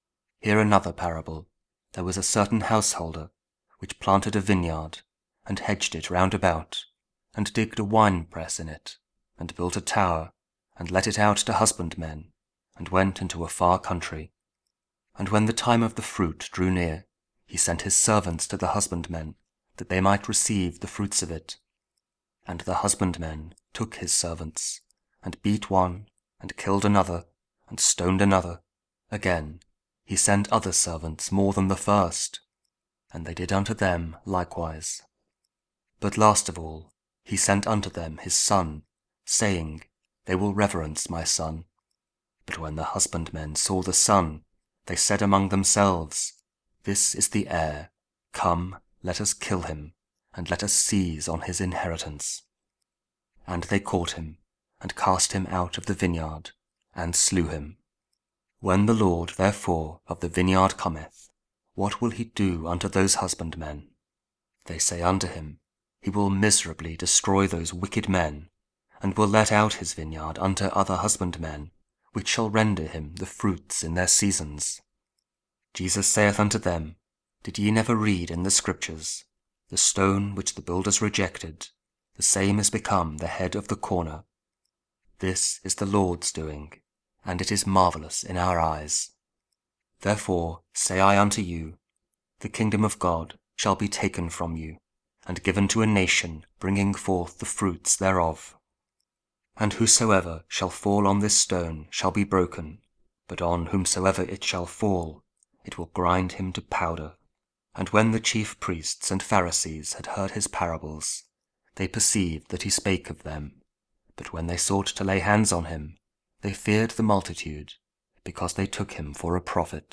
Audio Bible | Wicked Tennants